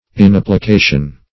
Search Result for " inapplication" : The Collaborative International Dictionary of English v.0.48: Inapplication \In*ap`pli*ca"tion\, n. [Pref. in- not + application: cf. F. inapplication.] Lack of application, attention, or diligence; negligence; indolence.
inapplication.mp3